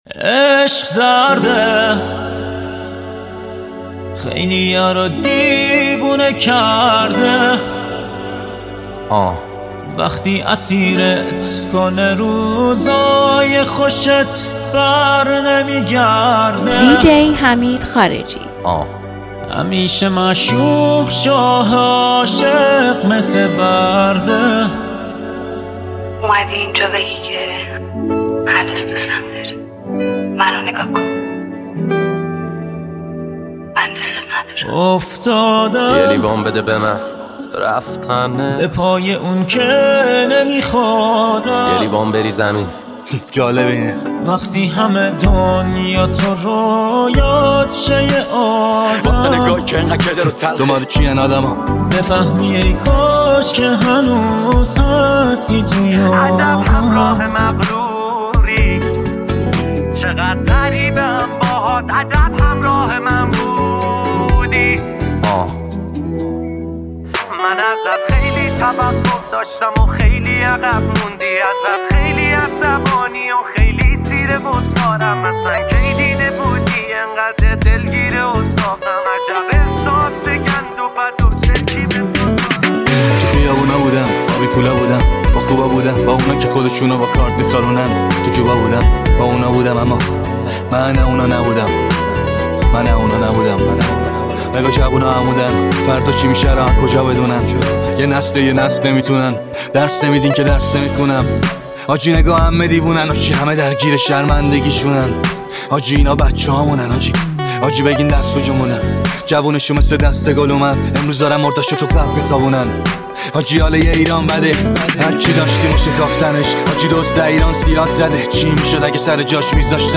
رپ فارسی